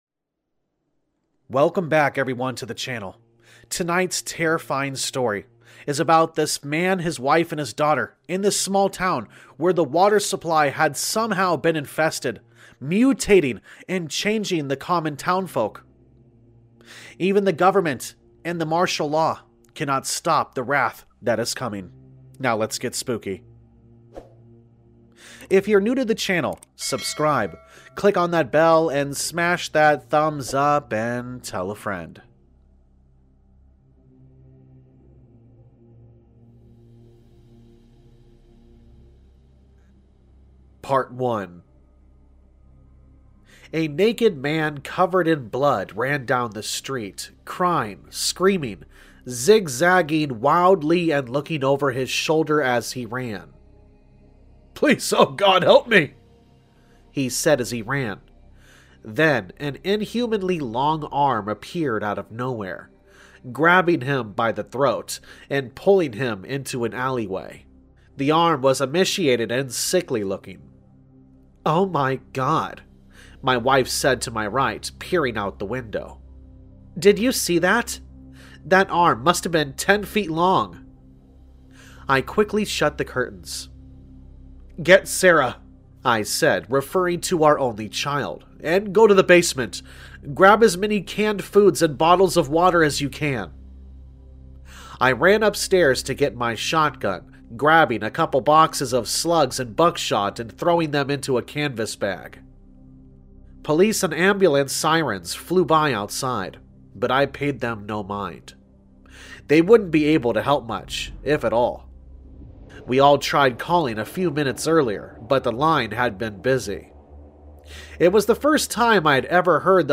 All Stories are read with full permission from the authors: Story Credit - CIAHerpes